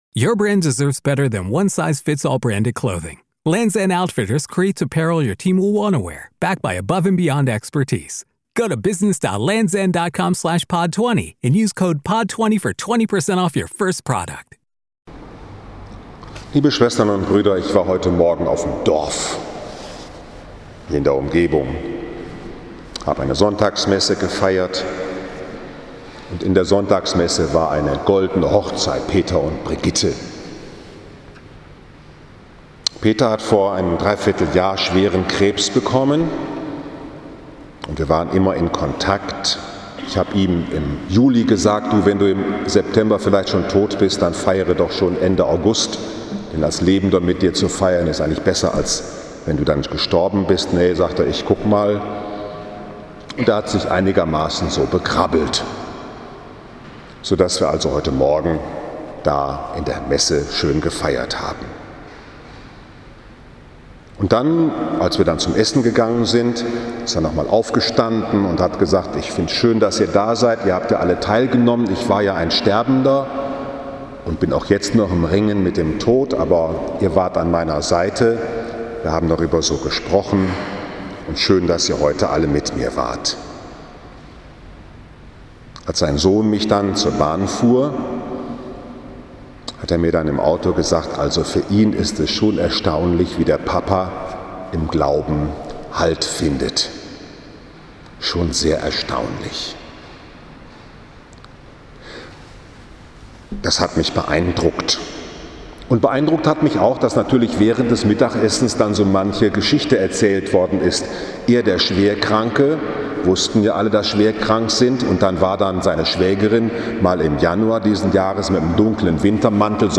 Wozu uns die Kraft Gottes drängt 29. September 2019, 20 Uhr, Liebfrauenkirche Frankfurt am Main, 26. So.i.J. C